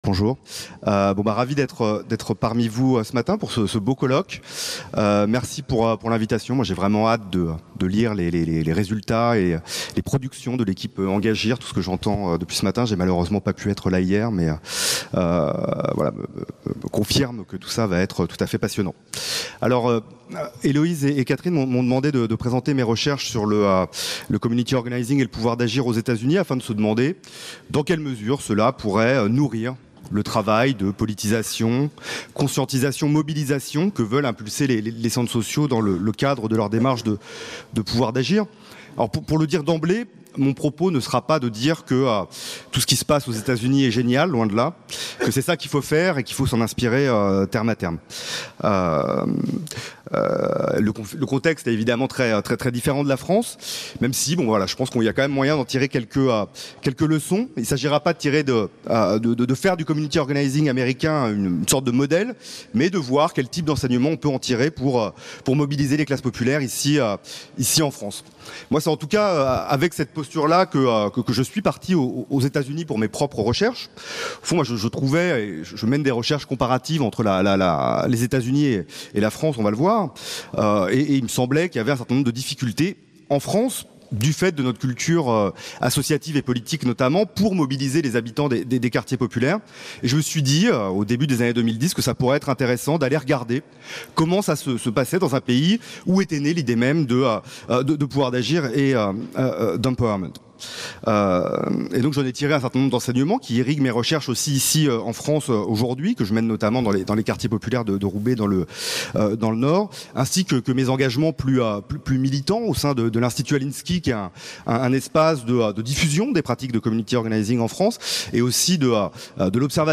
17- (Table ronde 3)